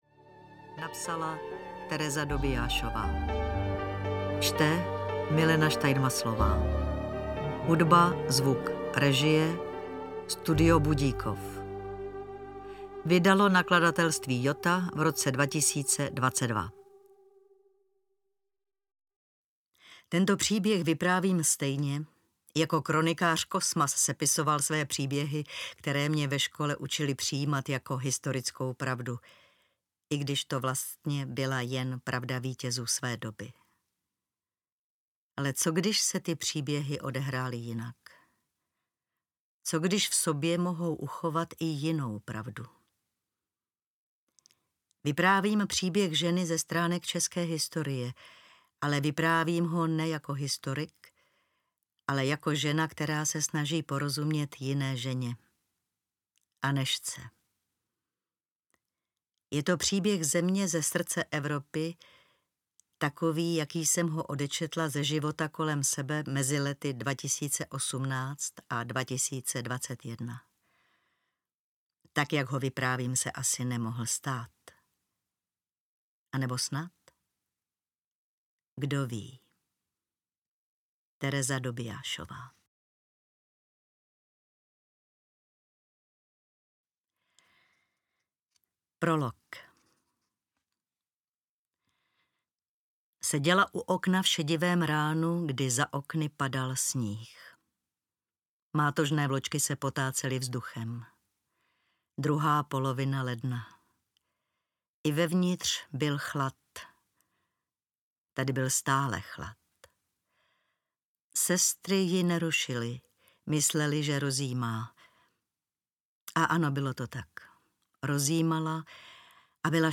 Tajemství audiokniha
Ukázka z knihy
| Čte Milena Steinmasslová. | Hudba, režie, střih a mastering Studio Budíkov.